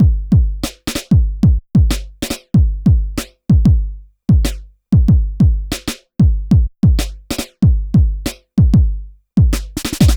Track 13 - Drum Break 04.wav